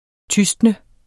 Udtale [ ˈtysdnə ]